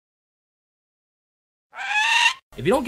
Sound Effects
Bird Noise